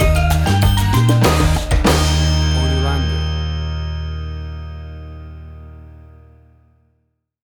Tempo (BPM): 96